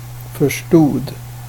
Uttal